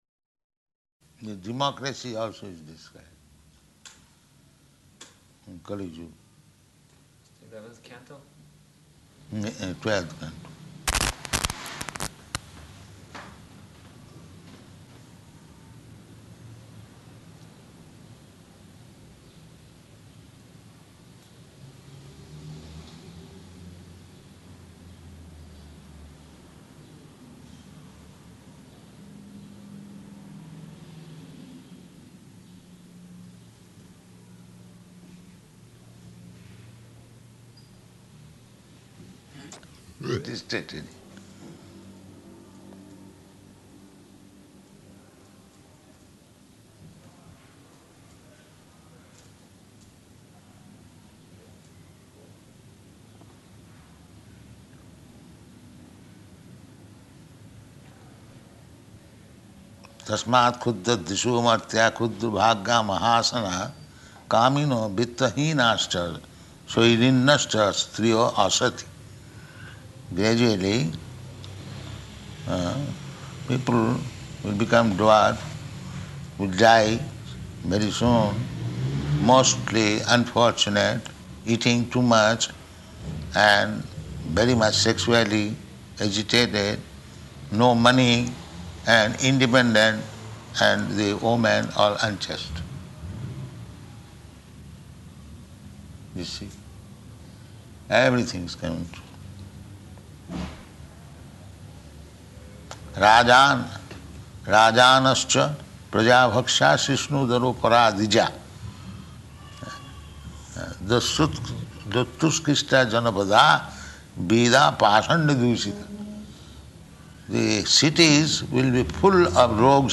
Room Conversation
Room Conversation --:-- --:-- Type: Conversation Dated: June 15th 1974 Location: Paris Audio file: 740615R1.PAR.mp3 Prabhupāda: The democracy also is described, in Kali-yuga.